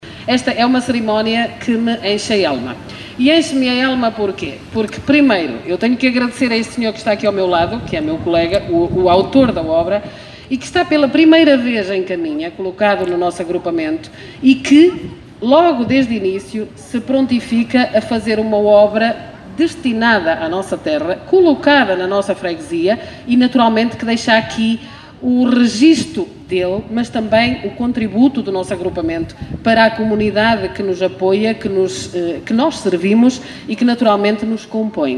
Foi inaugurada ontem, no Largo Sidínio Pais em Caminha, a escultura SCHOLA, um marco que presta homenagem à comunidade escolar do concelho, passada e presente.